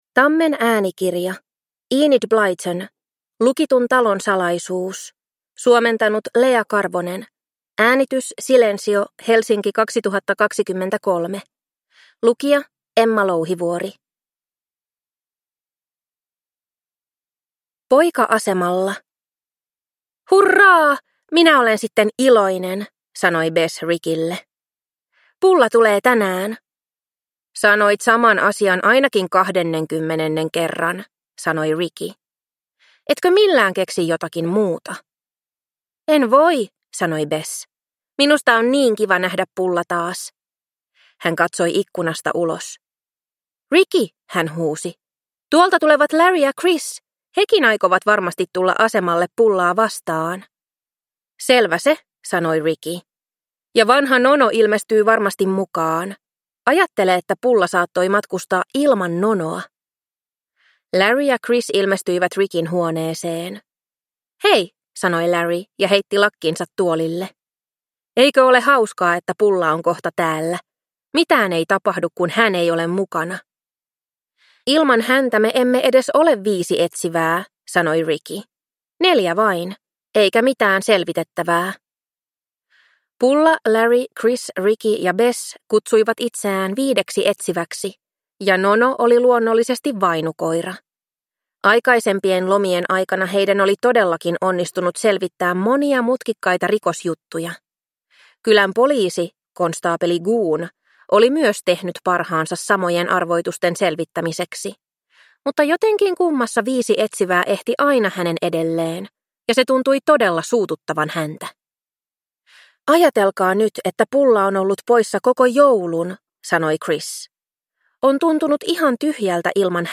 Lukitun talon salaisuus – Ljudbok – Laddas ner